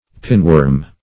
Pinworm \Pin"worm`\, n. (Zool.)